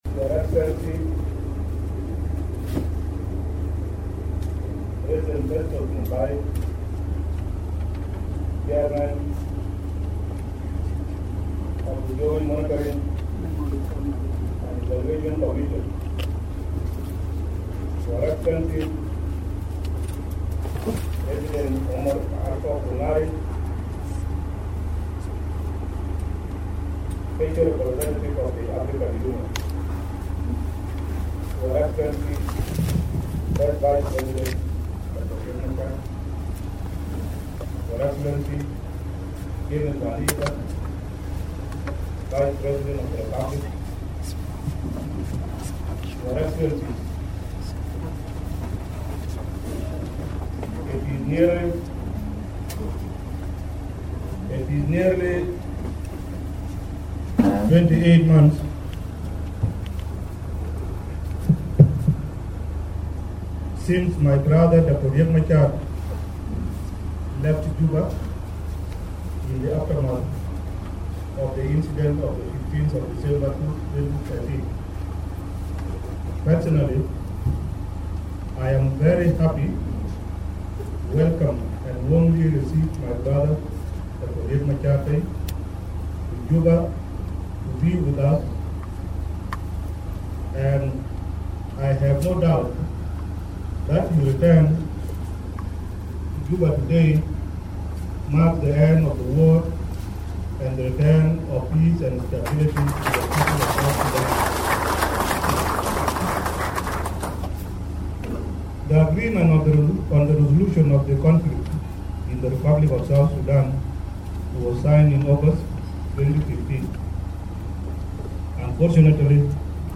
President Salva Kiir sounded strong words of unity and reconciliation at the swearing in ceremony of Vice President Riek Machar.
The swearing in took place at the President's office - J1 in Juba shortly after Machar's return to Juba on Tuesday.
We bring you President Kir's statement to partners and dignitaries invited to witness the ceremony.